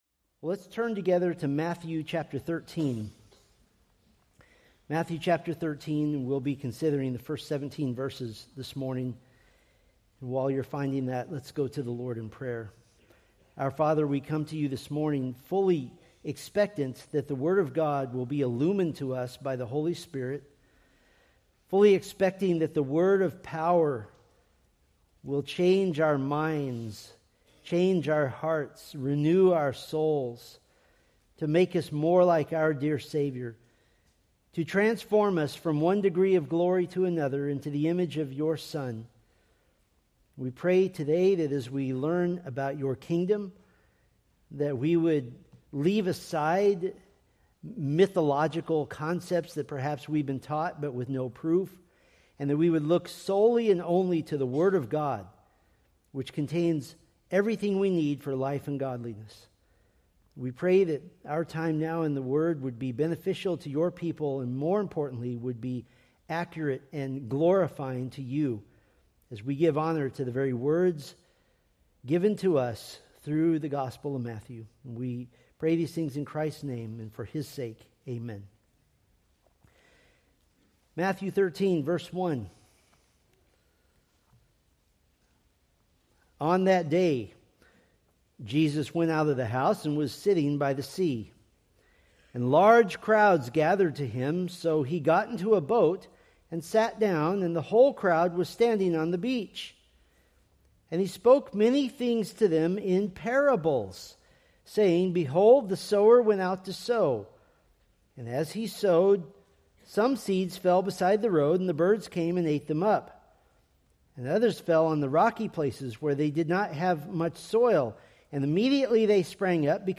Sermon Details